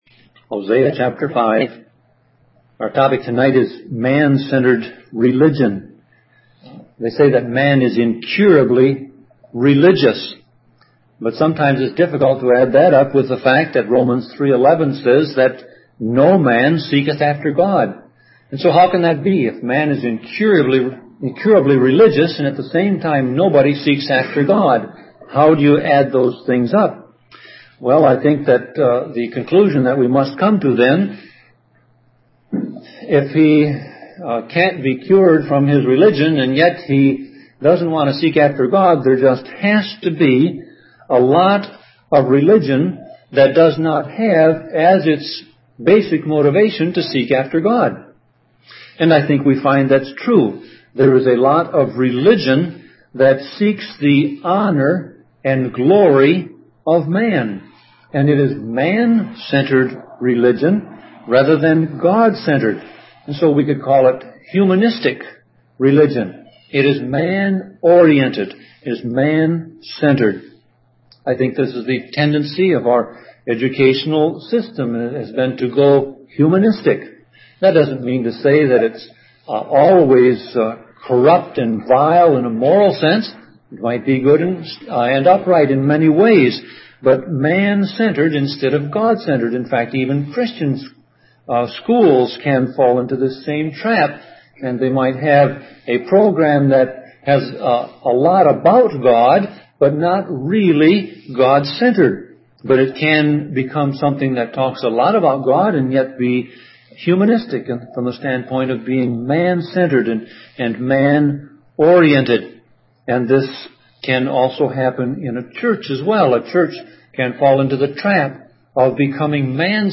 Sermon Audio Passage: Hosea 5 Service Type